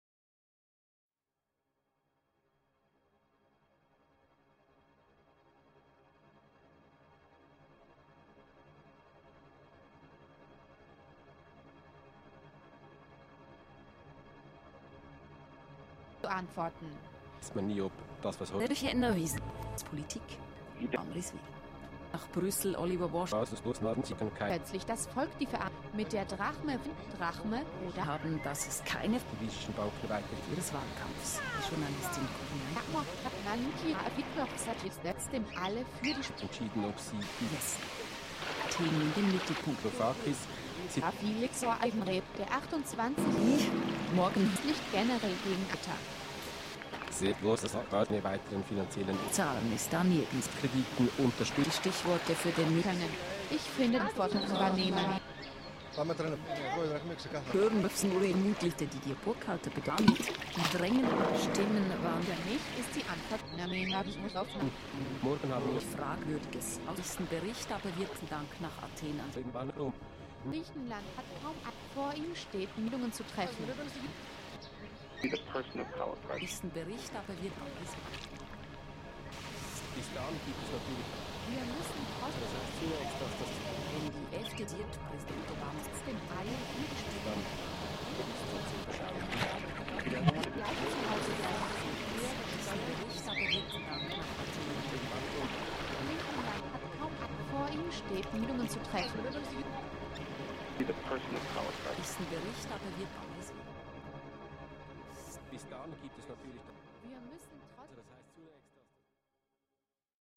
" I chose a recording from Switzerland for no other reason than that's where Dada began. Because I was working on it over the Martin Luther King holiday I worked in units of 1.18. I laid a grid over the source recording breaking it up in 1.18 second segments. I used a random number generator to rearrange the clips until I had a length of 1min, 18 seconds. I decided I wanted to collage in sound that related to the moment in time that I was working on this so I randomly recorded a German language news radio station coming out of Switzerland at the same time I was editing the first piece.
On both recordings I used a second set of randomly generated numbers to apply a small set of effects (backwards, reverb) to a few clips.